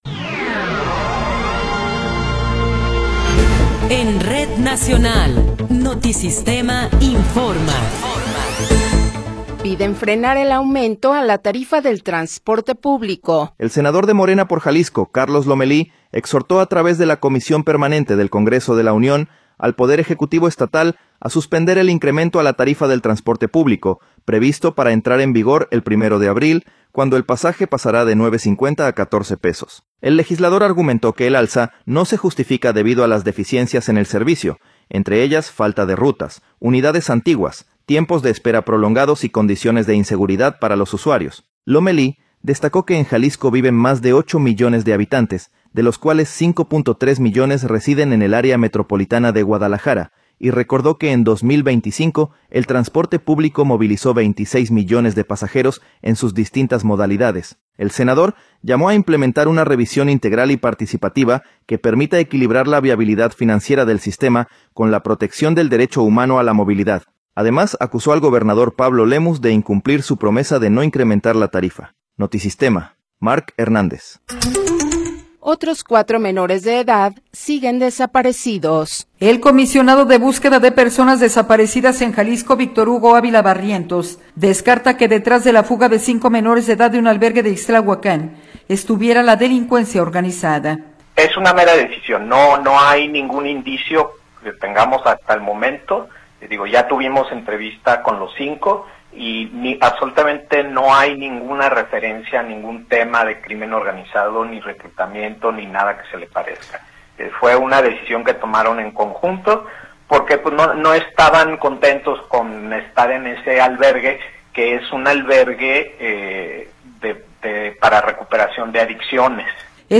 Noticiero 15 hrs. – 7 de Enero de 2026